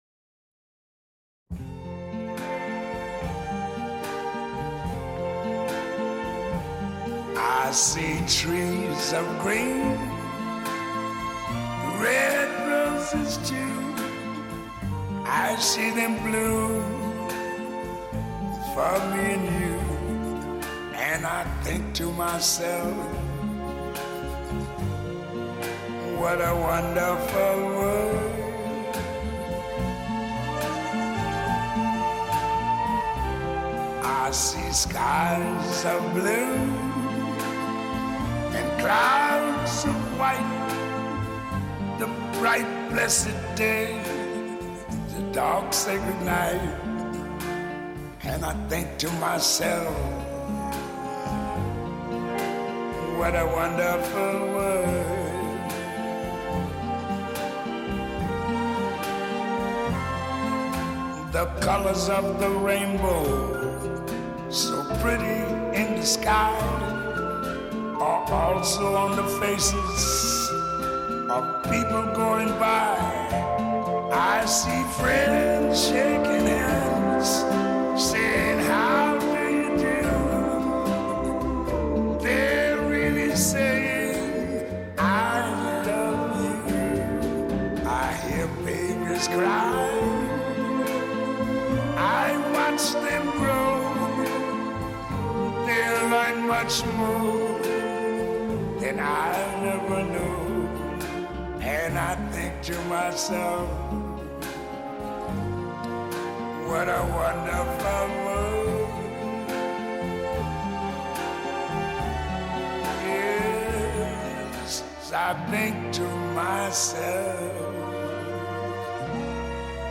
Track3_Jazz.mp3